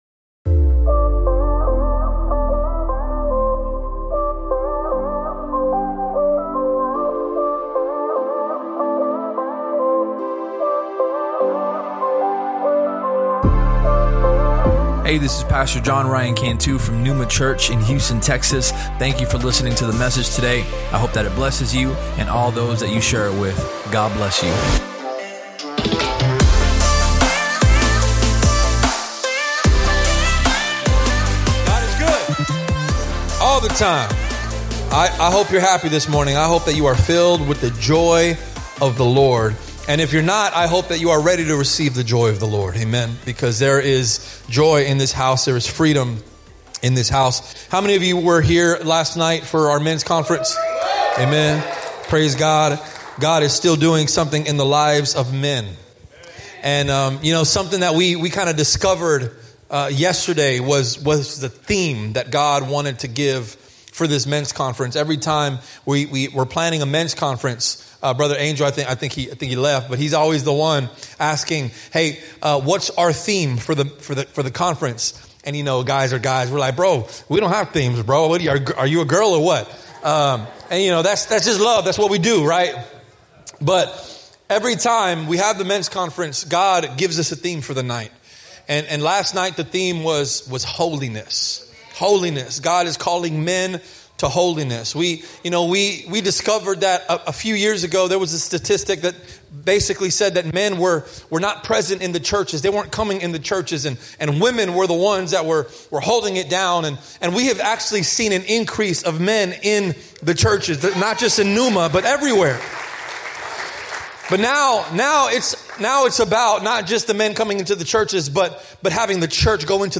Sermon Topics: Maturity If you enjoyed the podcast, please subscribe and share it with your friends on social media.